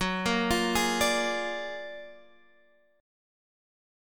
Listen to F#+M9 strummed